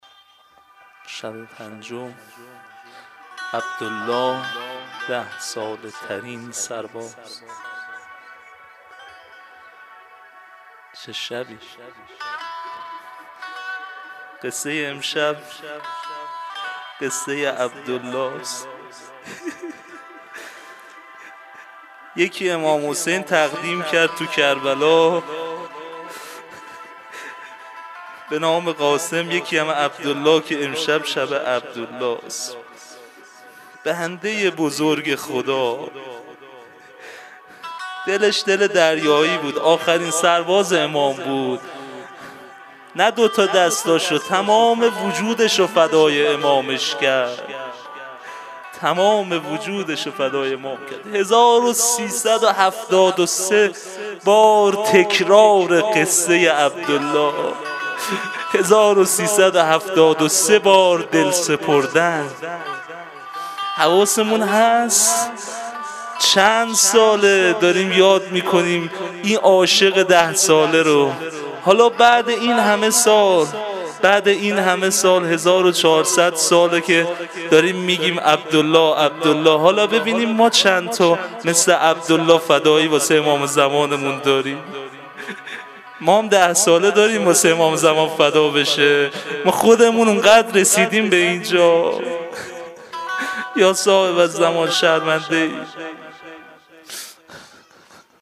مناجات شب پنجم